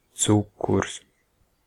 Ääntäminen
France (Paris): IPA: /sykʁ/